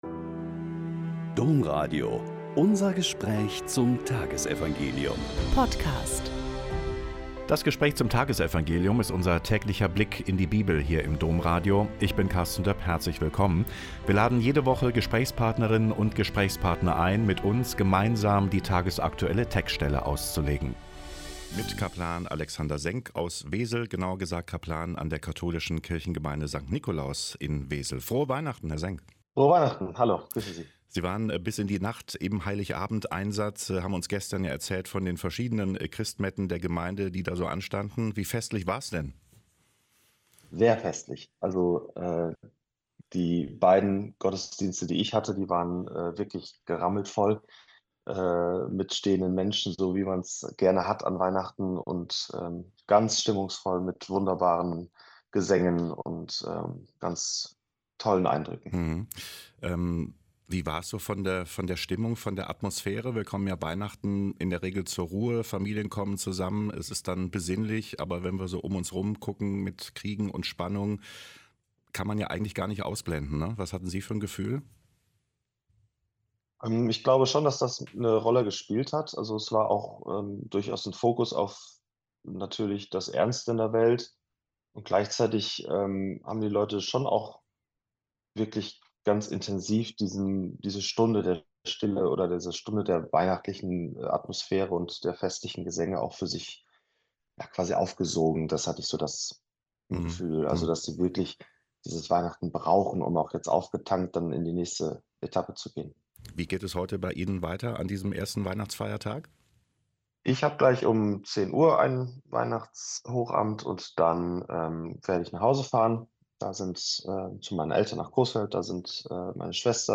Lk 2,15-20 - Gespräch